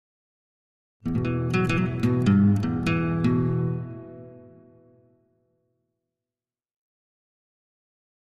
Acoustic Melody Version 1